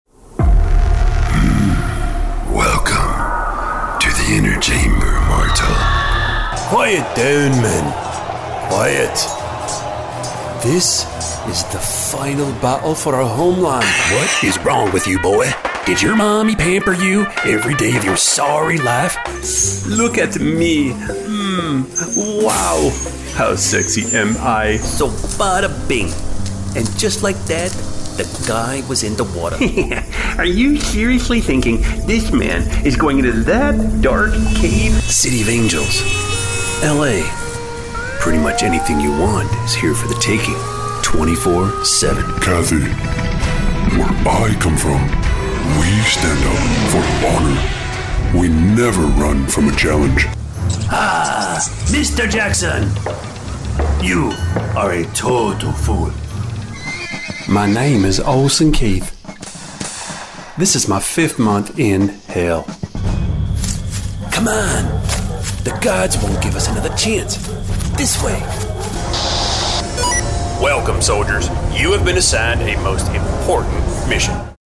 Sizzle Character Demo